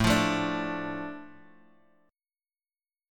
Asus4#5 chord